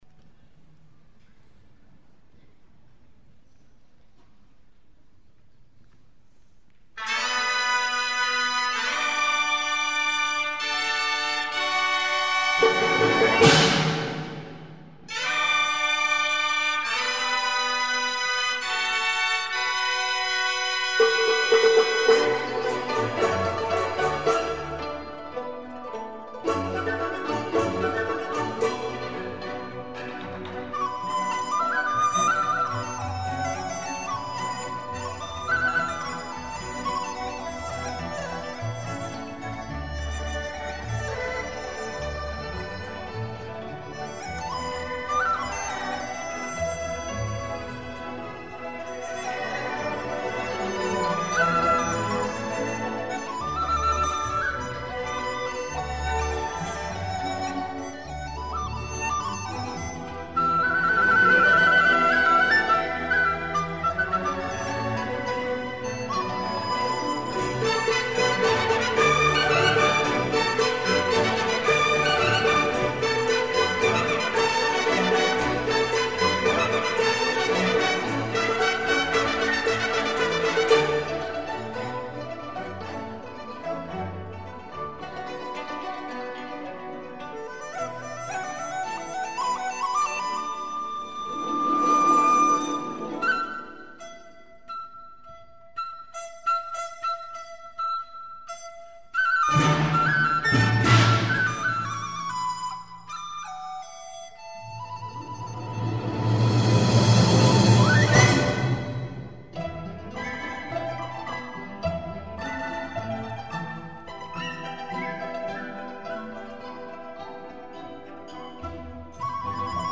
笛子协奏曲
古筝的华彩进入，铺垫出一个春色满园、梦回莺啭的妙境。
最后，乐队的全奏是一曲光辉、浩大的爱情颂歌。
此处笛子以虚吹吹孔和舌打音配合手指敲击笛身，营造了杜丽娘出现的场景。
笛子的旋律流利顺畅、华美飘逸，乐队的情绪则爽朗明快，集中地展现出了欢庆热烈的场面和爱情的无限欢悦。